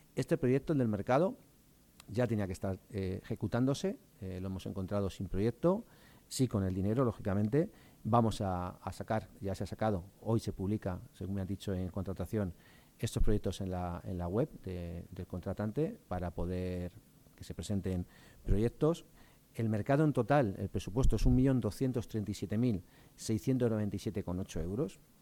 El portavoz del equipo de Gobierno, Juan José Alcalde, ha destacado, esta mañana, en rueda de prensa, que el Ayuntamiento de Toledo sigue trabajando en la ejecución de los fondos europeos para cumplir con los plazos previstos y evitar que se pierda el dinero.